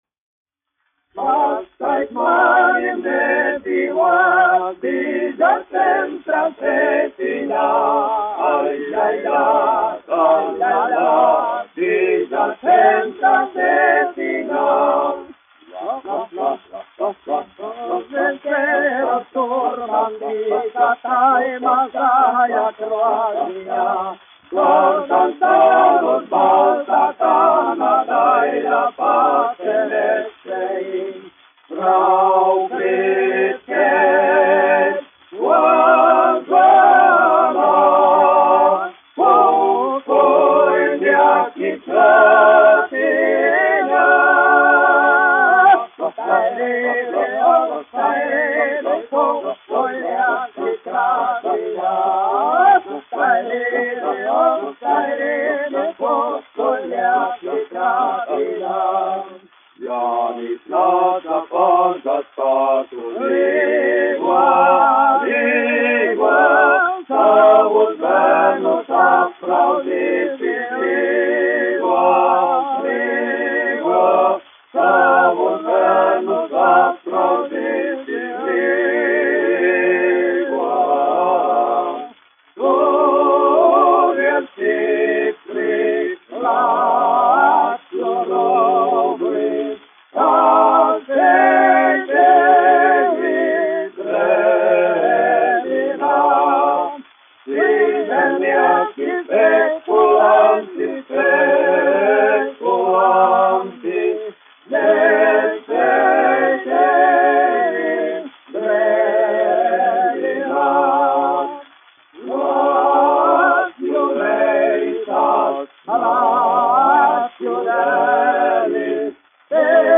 1 skpl. : analogs, 78 apgr/min, mono ; 25 cm
Vokālie seksteti
Latviešu tautasdziesmu aranžējumi